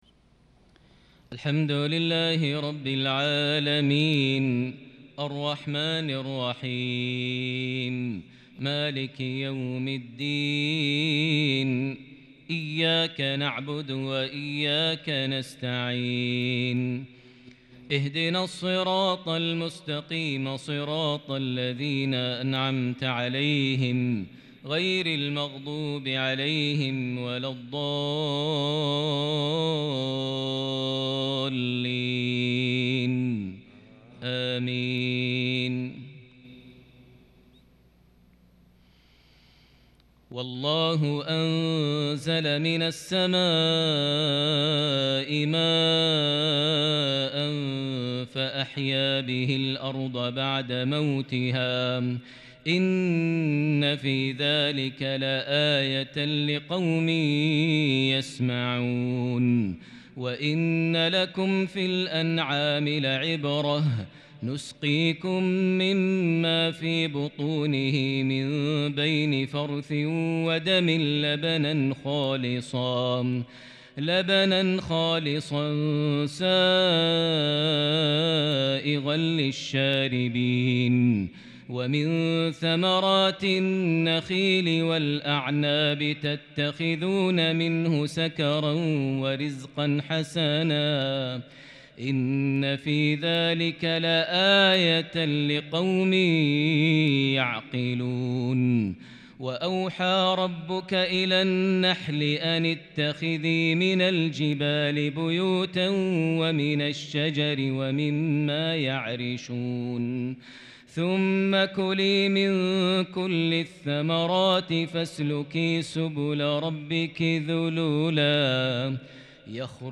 انتقاءات عذبة فريدة من سورة النحل| الاثنين 23 ذي الحجة 1442هـ > 1442 هـ > الفروض - تلاوات ماهر المعيقلي